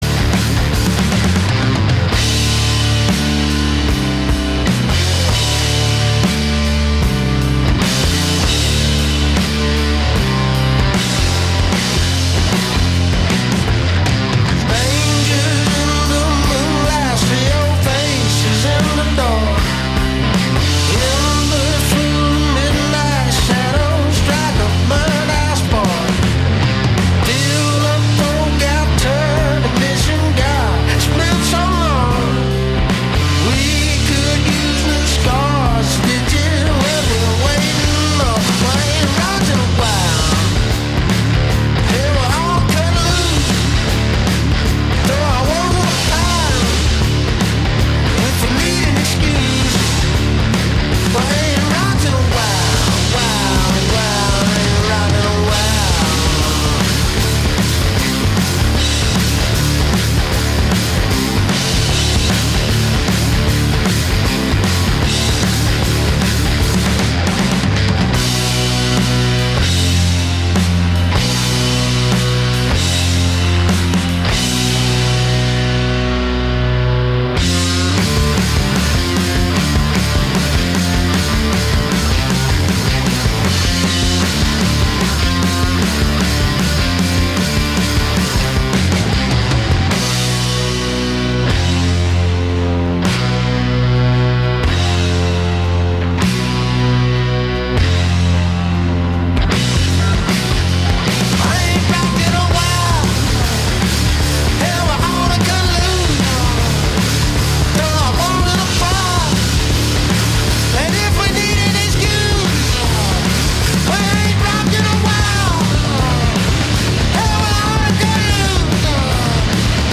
Indie trio